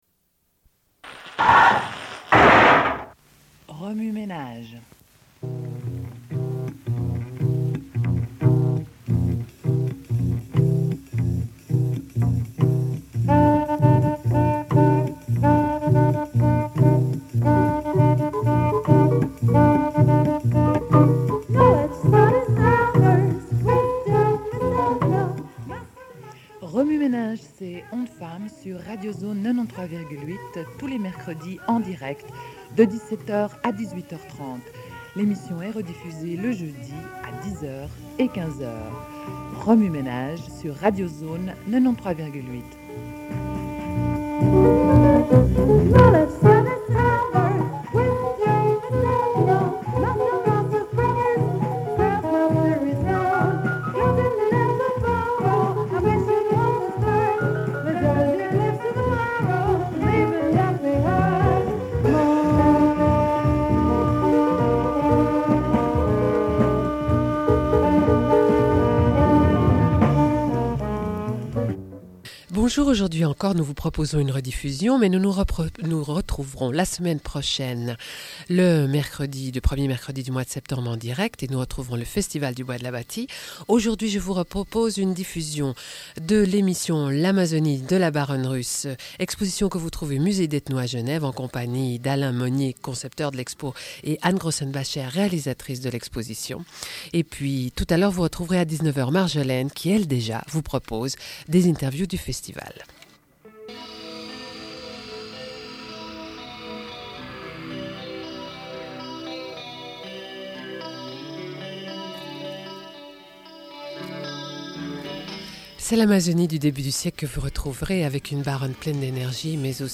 Une cassette audio, face A31:48